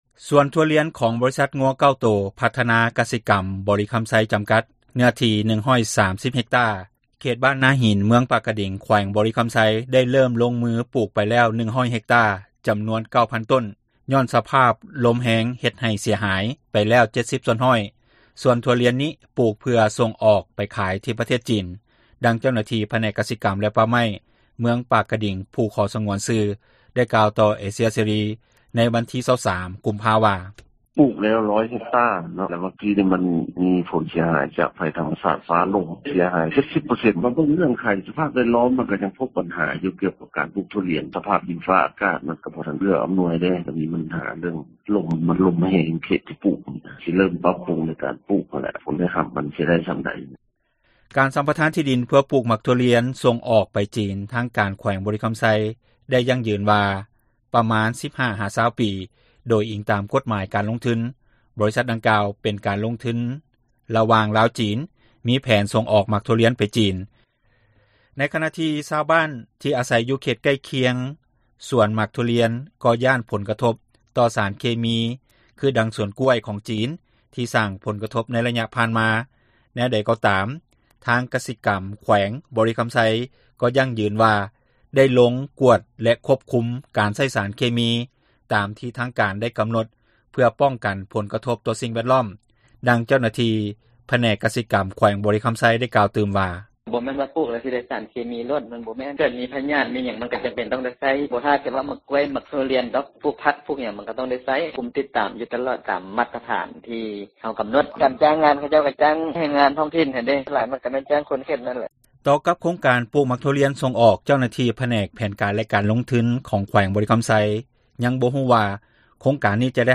ສວນໝາກຖົ່ວລຽນ ຂອງຈີນ ເສັຽຫາຍກາຍເຄິ່ງ — ຂ່າວລາວ ວິທຍຸເອເຊັຽເສຣີ ພາສາລາວ